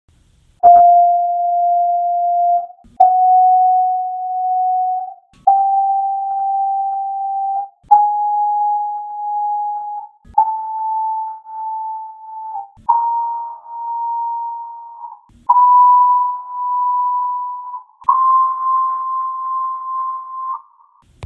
This is a free analysis generated by a recording of M3’s and P4’s submitted to me by one of my subscribers.
Goal: 0.5 bps to 1.5 bps
P4, filtered
2. Listen to the filtered recordings and try to confirm aurally that the beat speeds are following what the graph says.